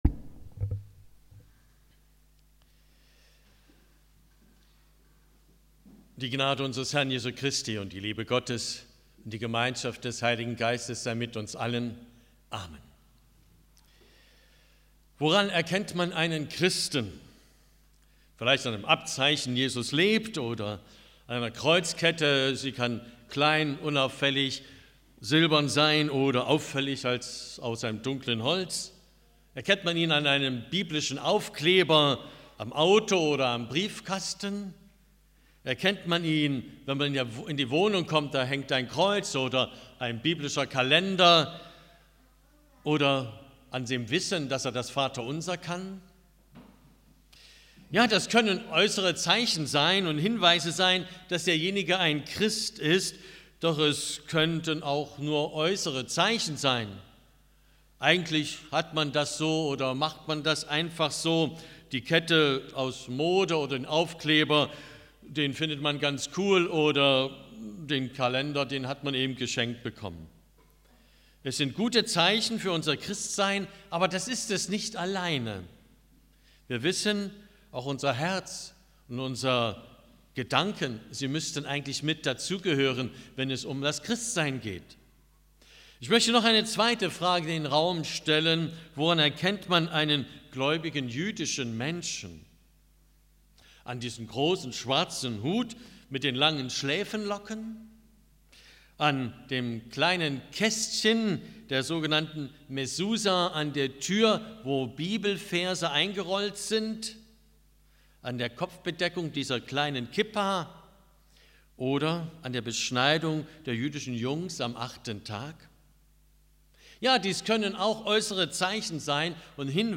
Meldung Predigt 29.05.2022